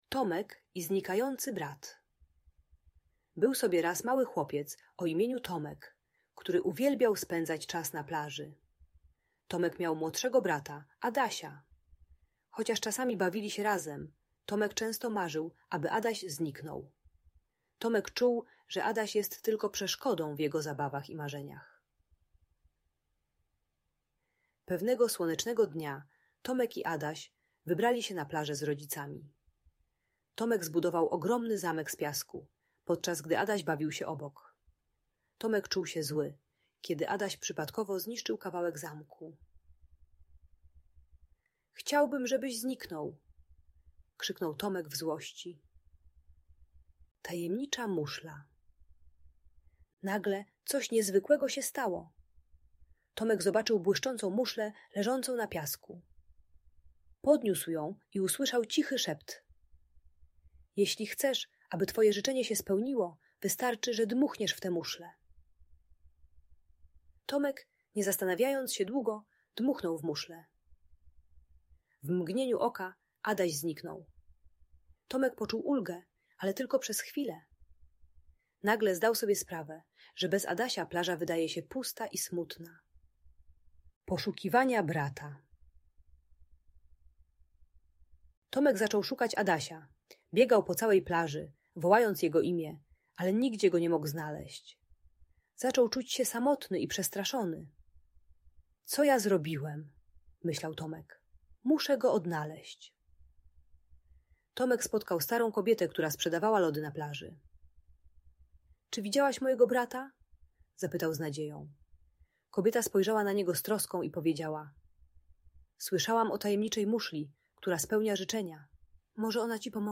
Tomek i Znikający Brat: Niezwykła historia - Audiobajka